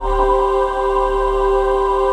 VOICEPAD10-LR.wav